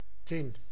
Ääntäminen
Ääntäminen Tuntematon aksentti: IPA: /tɪn/ Haettu sana löytyi näillä lähdekielillä: hollanti Käännös Ääninäyte Substantiivit 1. tin US Suku: n .